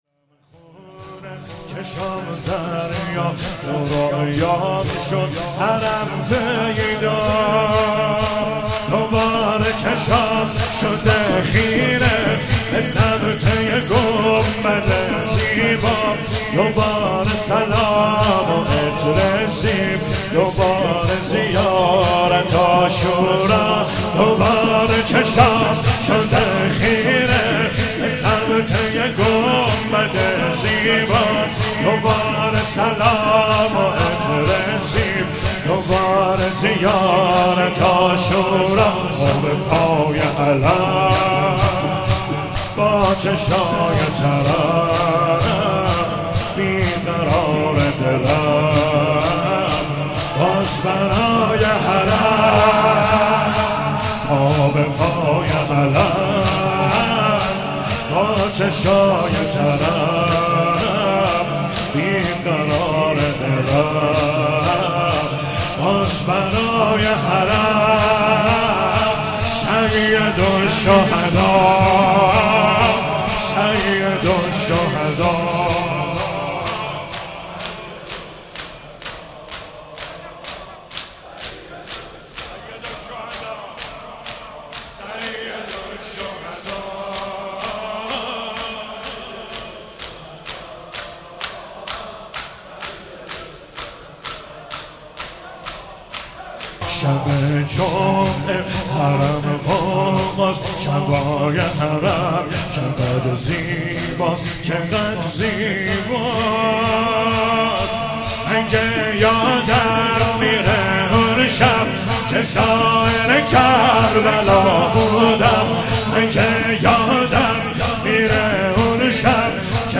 شور - دلم خونه چشام دریا تو رویام شد حرم پیدا